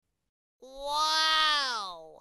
ooohhh_642bXMi.mp3